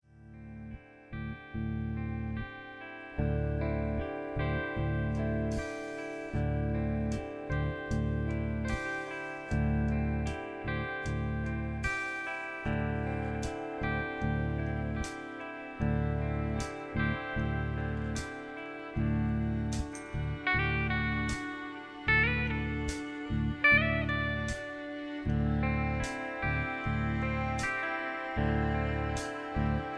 Professional Backing Tracks With Backing Vocals
Singers & Karaoke(Stereo/Surround Sound) mp3 format
backing tracks
country